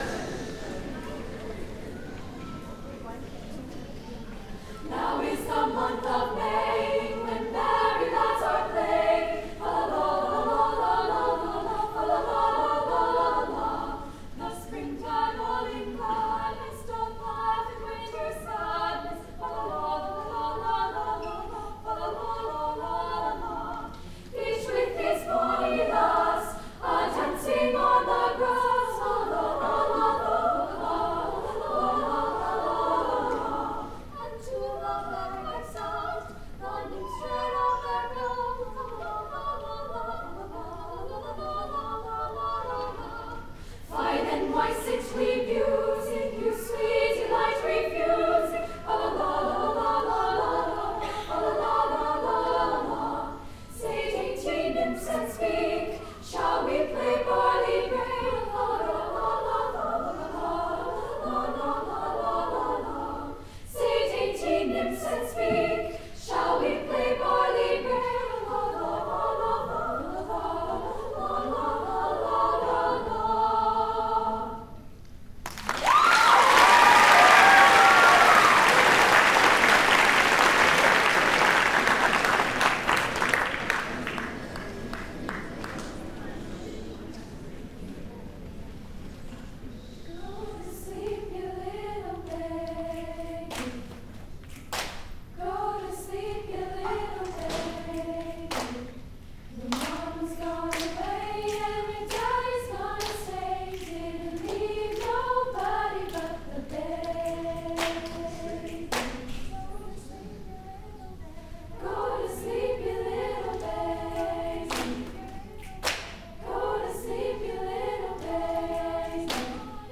Fall 2016 Concert Tracks